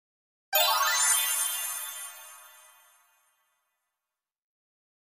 Play, download and share magic reveal 2 original sound button!!!!
magic-reveal-2.mp3